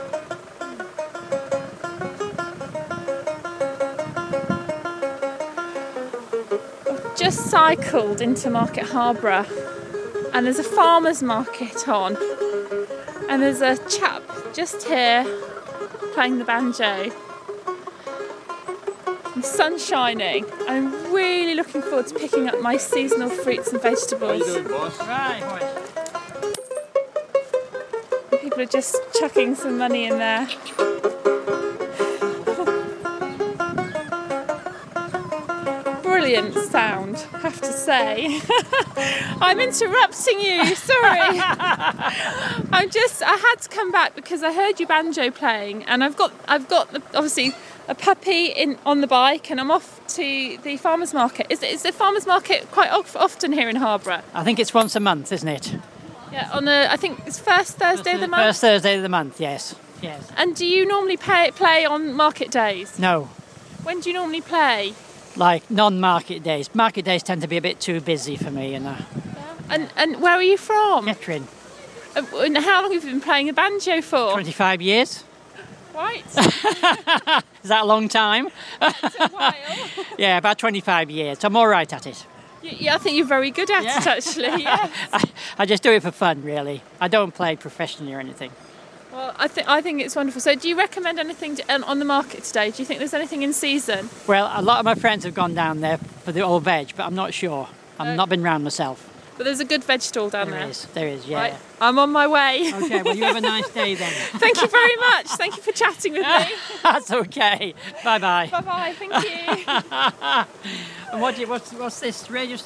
Banjo player